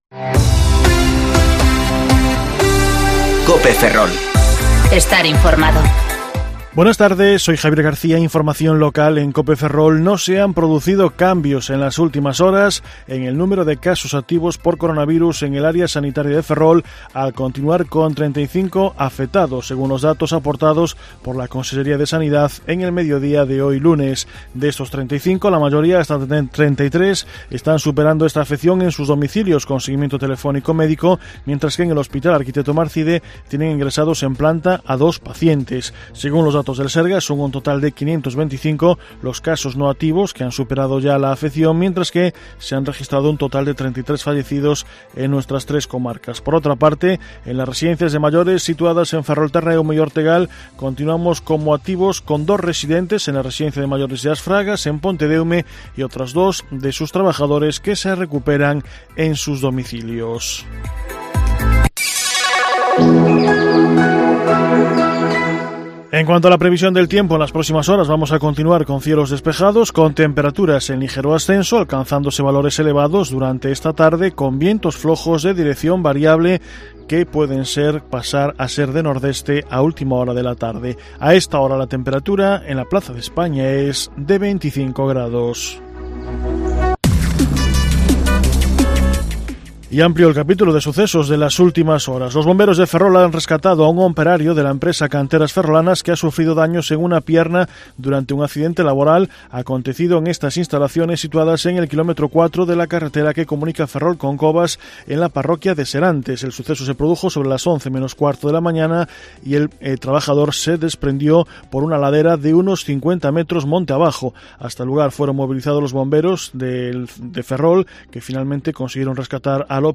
Informativo Medidía COPE Ferrol - 18/5/2020 (De 14,20 a 14,30 horas)